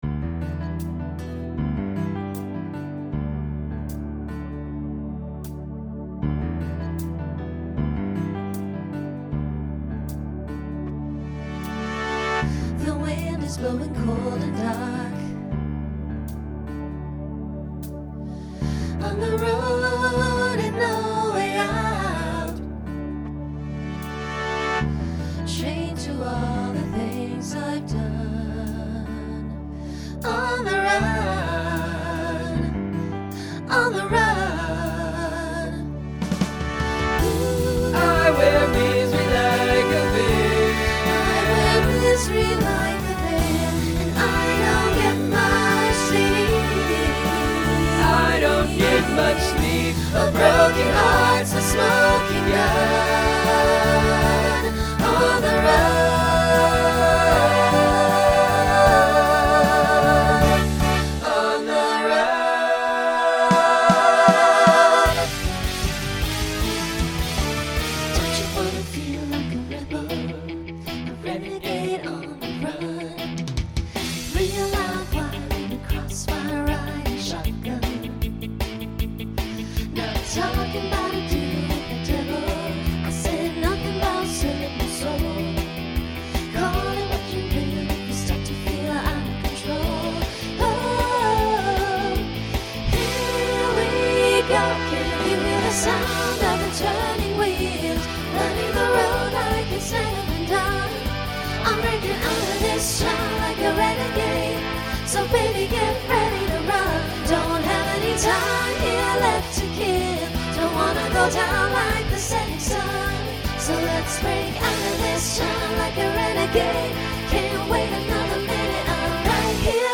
Genre Rock
Voicing SATB